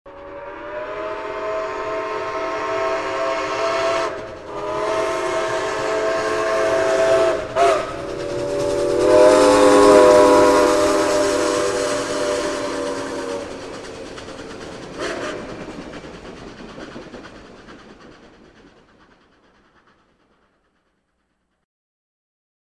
Скачать звук паровоза — стук колес, с гудком, движение внутри
С гудками проходит мимо